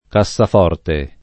cassaforte [ ka SS af 0 rte ]